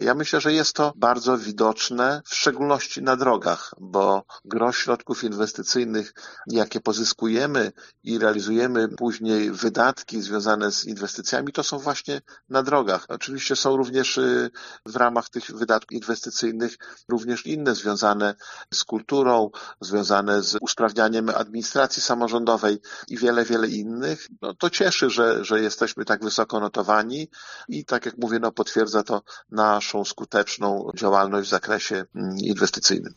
Starosta dodaje, że wysoki wynik związany jest z istotnymi inwestycjami: